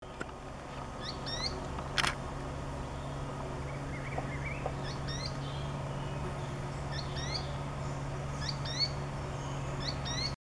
Azara´s Spinetail (Synallaxis azarae)
Life Stage: Adult
Location or protected area: Parque Nacional Calilegua
Condition: Wild
Certainty: Photographed, Recorded vocal